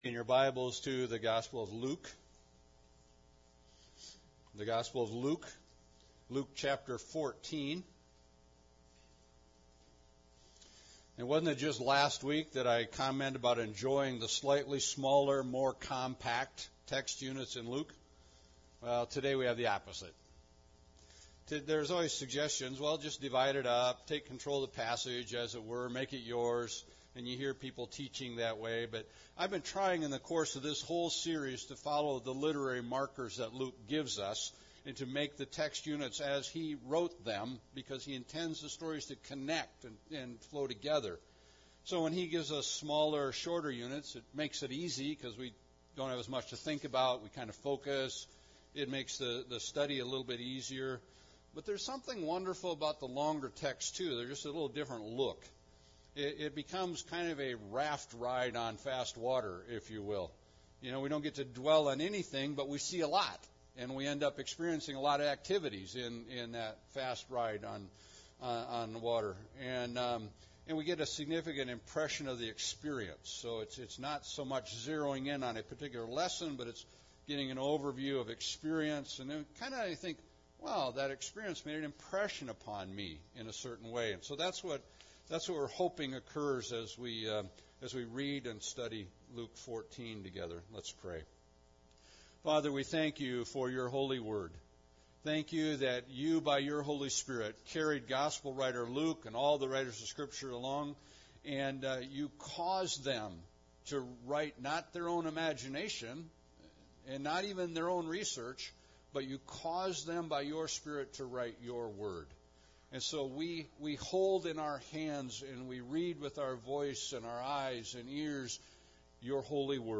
Luke 14:1-35 Service Type: Sunday Service Bible Text